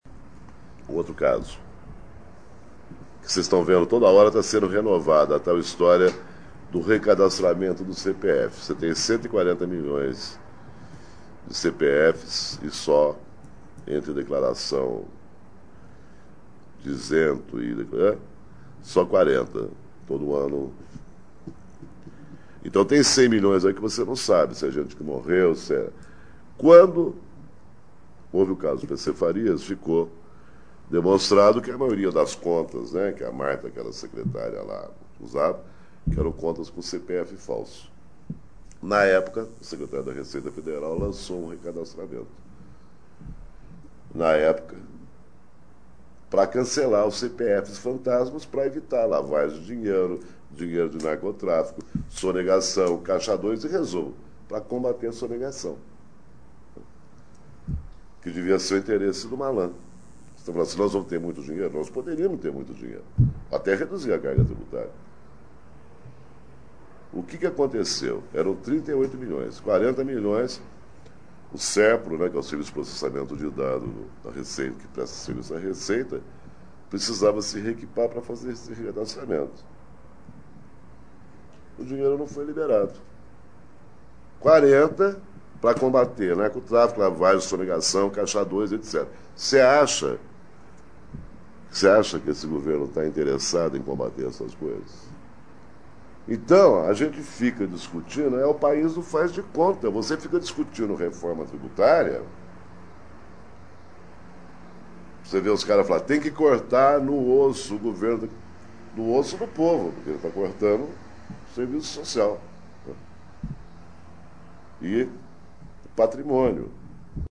Caso do país do "faz de conta" Repórter do Futuro, na Oboré Gravado em São Paulo, 27/11/1999 Aloysio Biondi cita o exemplo da má gestão da administração pública para falar que o país poderia ter mais dinheiro para investimento se fiscalizasse melhor seus recursos.
Também falou do rombo das privatizações, os dilemas das esquerdas e o posicionamento político dos jornais na era FHC. Abaixo, ouça trecho do áudio da palestra.